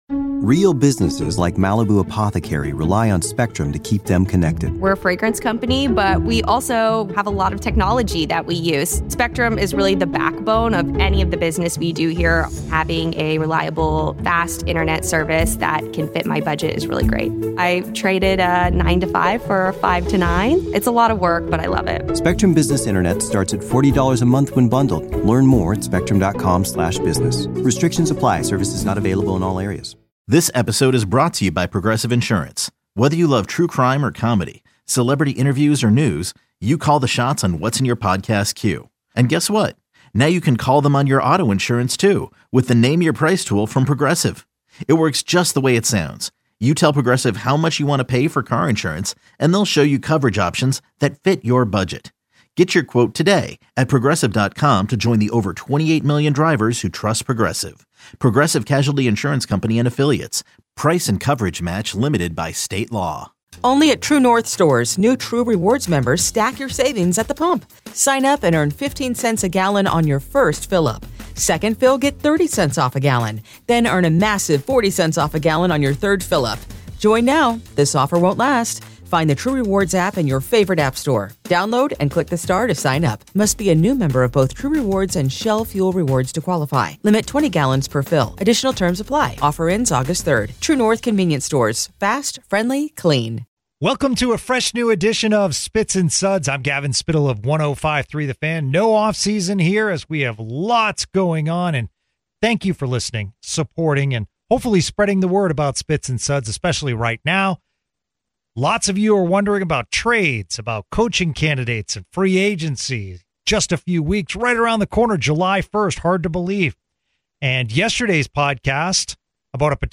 Cowboys' head coach Brian Schottenheimer joins the show to preview Commanders and talk about CeeDee being back Sunday. CeeDee Lamb is BACK.